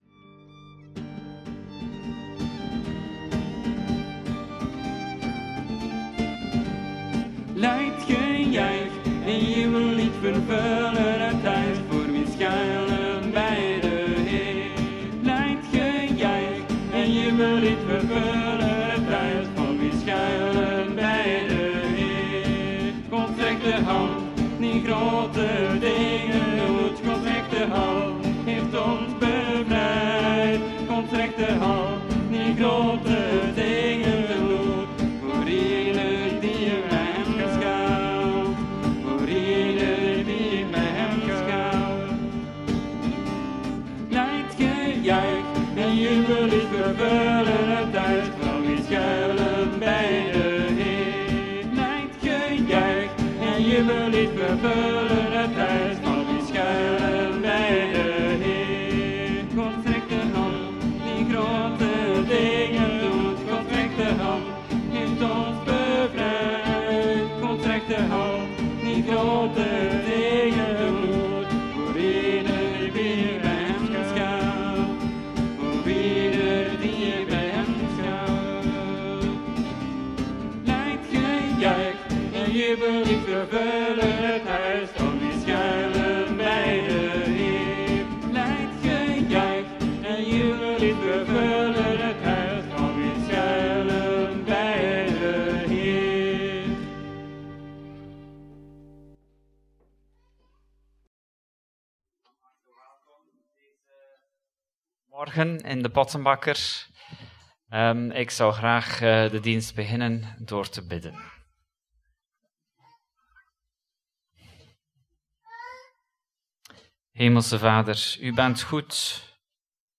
We starten een nieuwe reeks preken over de zeven hoofdzonden.